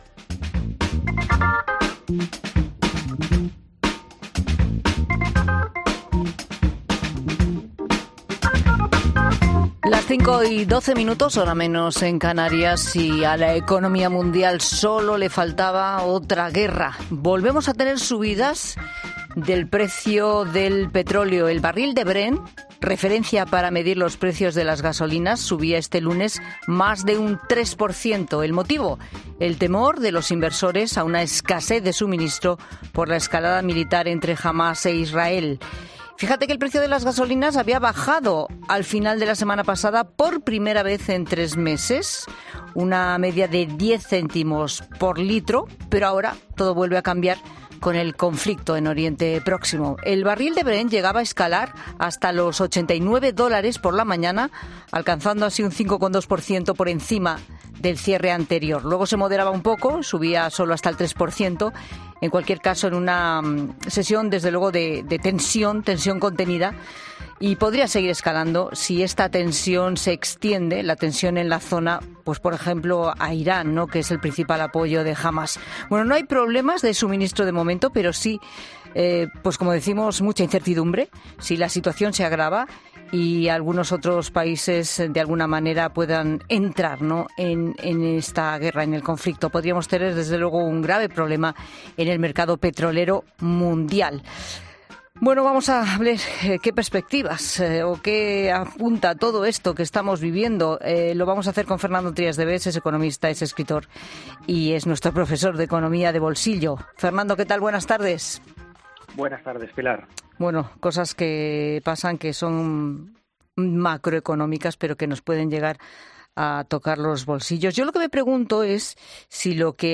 El economista explica en 'La Tarde' de COPE cómo la guerra puede acelerar un proceso de subida de precios y en qué medida afectaría a Europa